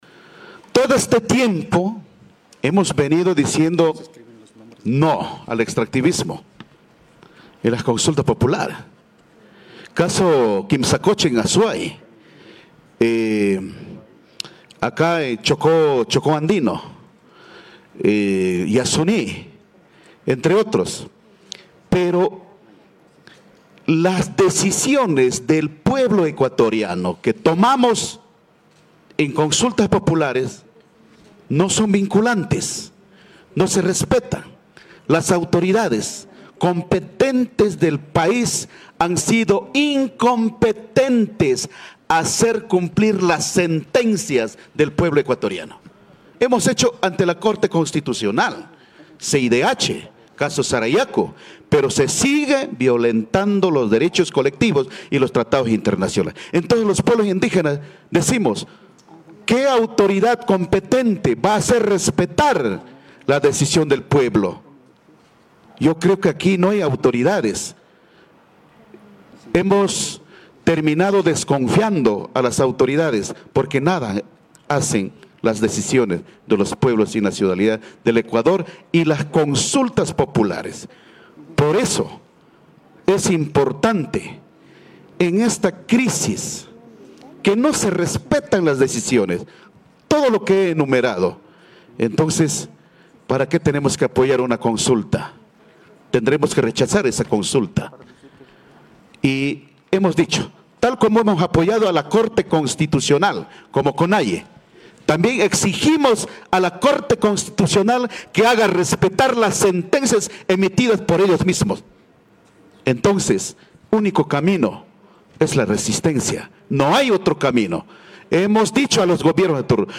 Indígenas de la Nacionalidad Waorani de las provincias Pastaza, Orellana y Napo se trasladaron el 8 de septiembre hasta Quito capital del Ecuador para mediante una rueda de prensa denunciar y rechazar, a varios sectores por haberles calificado de ser parte de los grupos que realizan actividades mineras en sus territorios.
Marlon Vargas, presidente CONAIE.